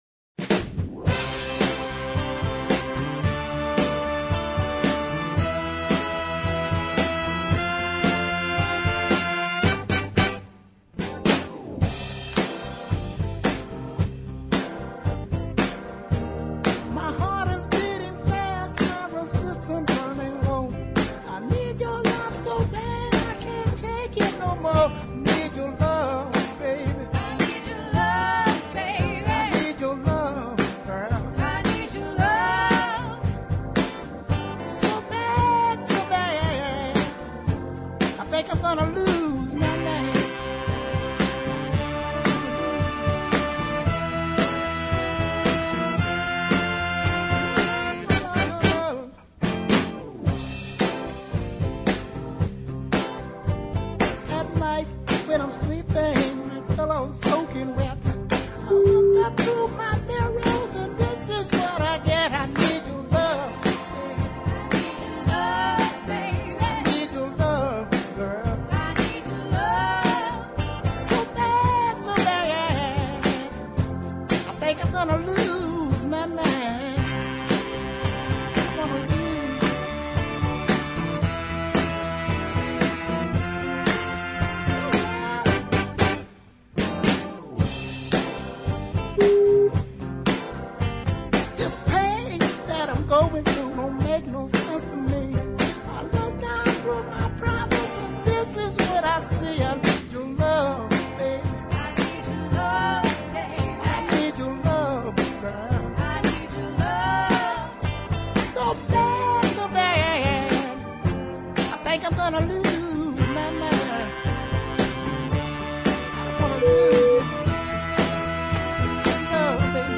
the mid paced songs
unique "quavering" vocals